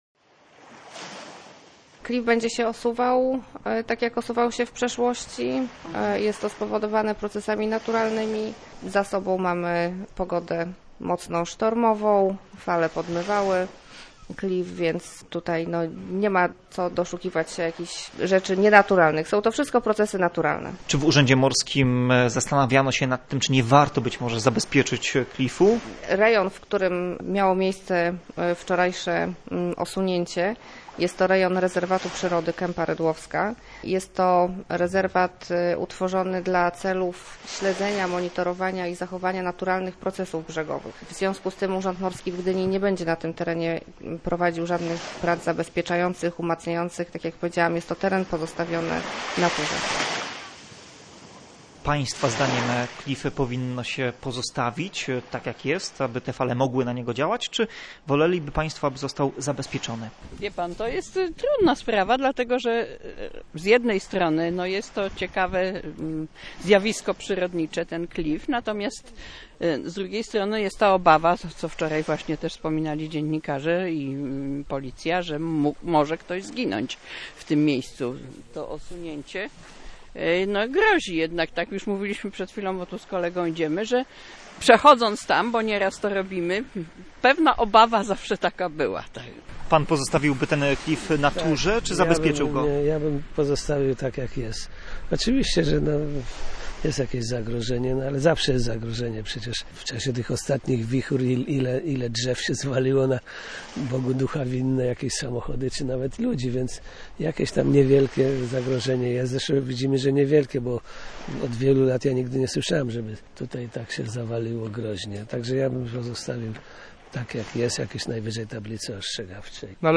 Gdynianie, z którymi rozmawiał nasz reporter, są podzieleni.